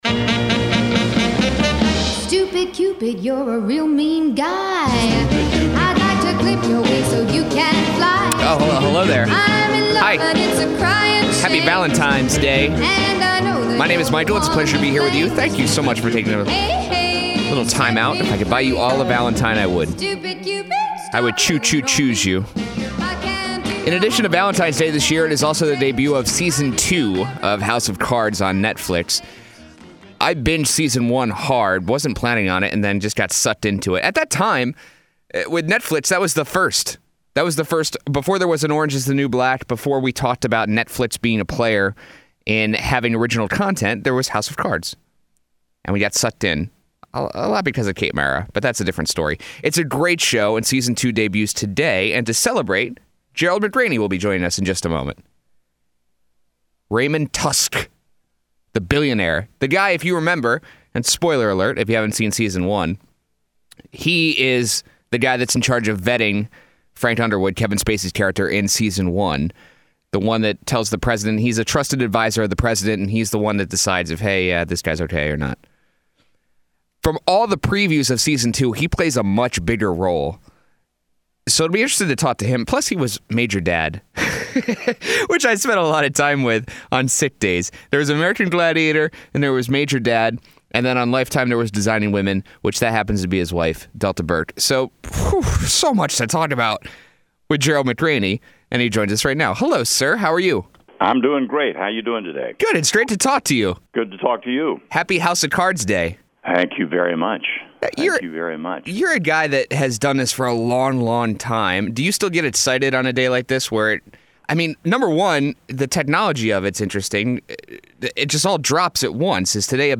Happy ‘House Of Cards’ Day: Celebrate With This Gerald McRaney Interview
gerald-mcraney-interview.mp3